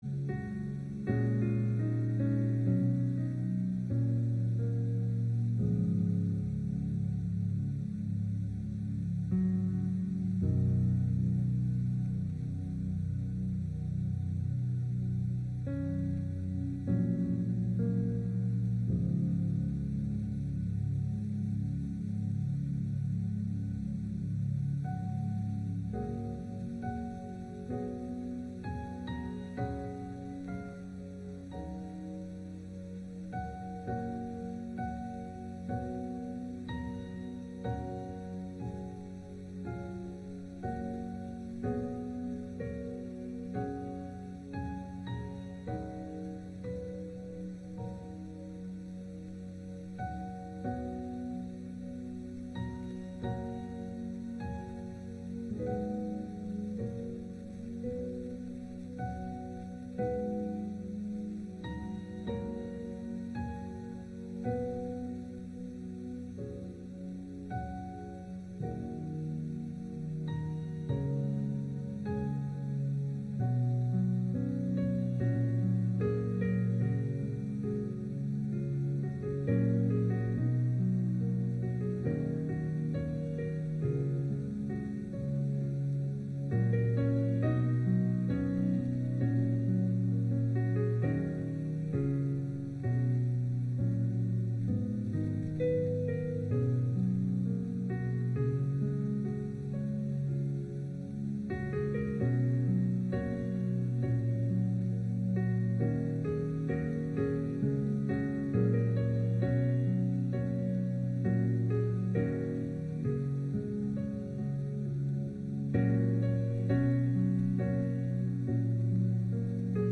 Morning Worship
September 13 Worship Audio – Full Service September 13 Sermon Audio Bible References Luke 7:36